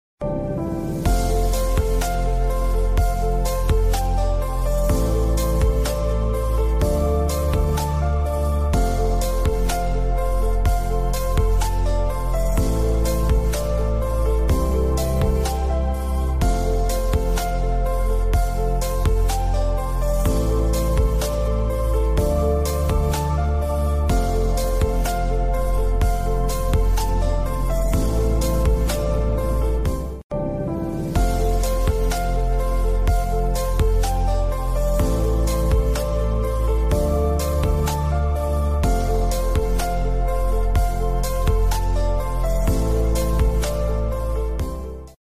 Discover DOWELL3D Best Selling 3D Printer sound effects free download